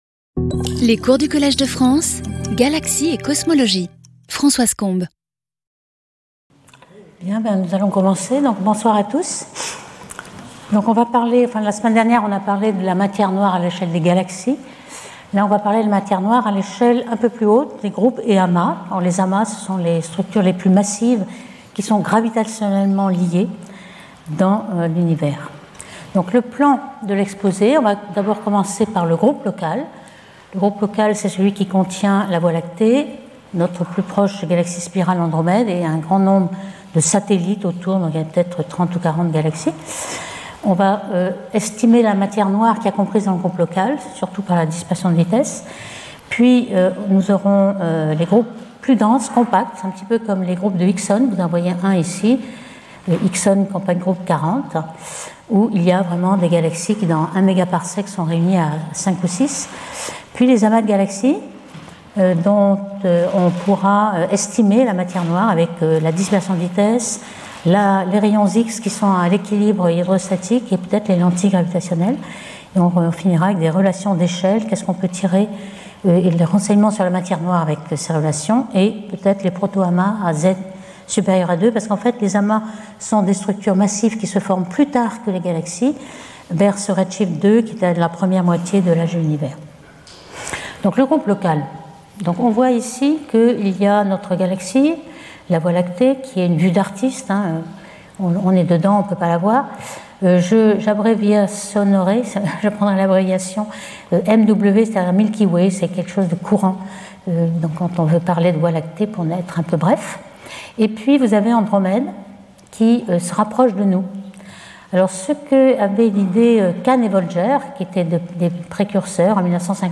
Françoise Combes Professeure du Collège de France